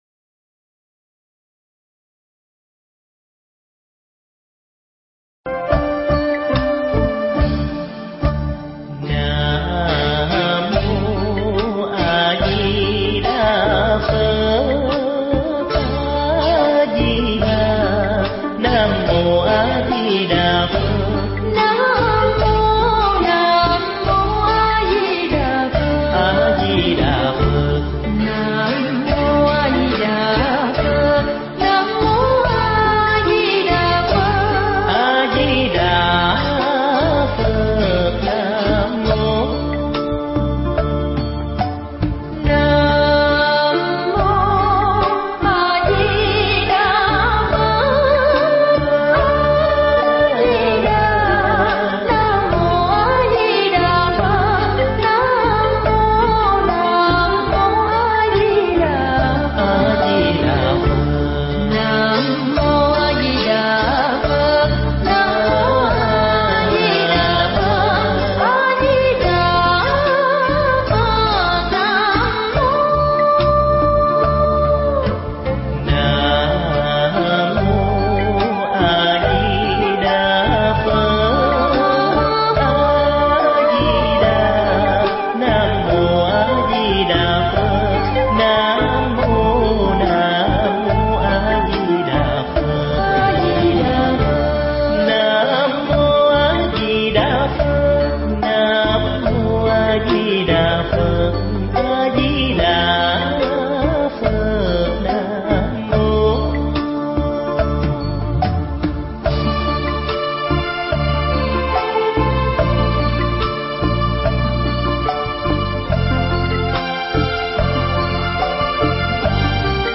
Mp3 Thuyết Giảng Những Bài Học Lớn Qua Chư Phật Chư Tổ
giảng tại Tịnh Xá Ngọc Chơn, Tỉnh Tây Ninh